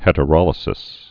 (hĕtə-rŏlĭ-sĭs, -ə-rō-līsĭs)